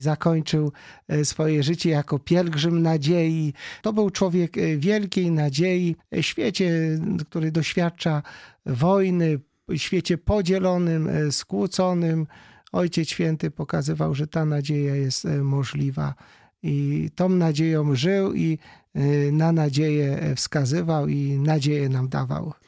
W rozmowie z Radiem Rodzina opowiedział nie tylko o wspomnieniach jakie pozostaną w nim po papieżu, ale też o przesłaniu jego pontyfikatu.